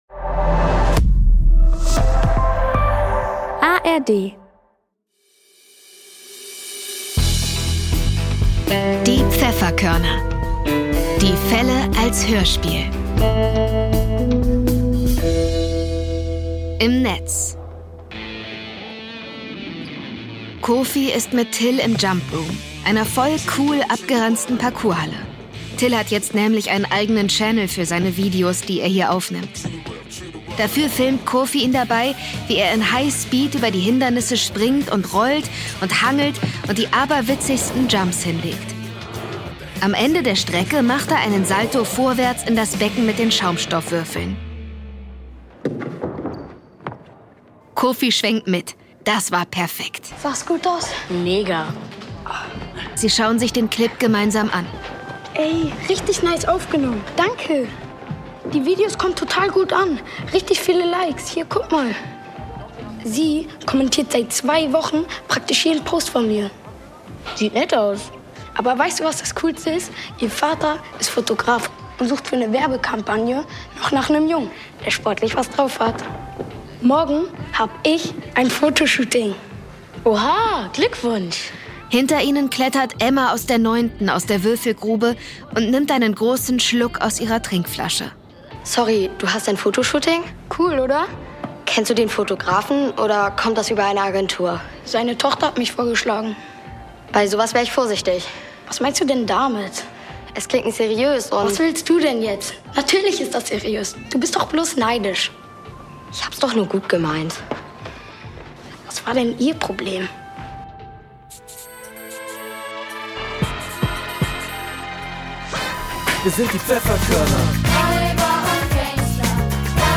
Im Netz (5/21) ~ Die Pfefferkörner - Die Fälle als Hörspiel Podcast